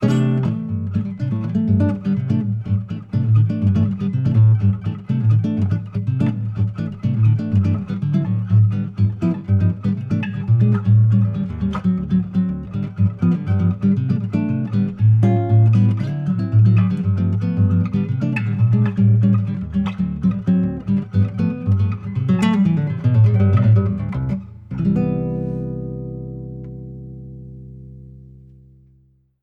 7 cordes Do Souto 2004
Un » violão de 7 cordas », acheté lors de mon premier voyage à Rio de Janeiro.
J’ai eu la chance de pouvoir la faire restaurer, mais depuis je la monte avec des cordes nylon au lieu des acier d’ origine.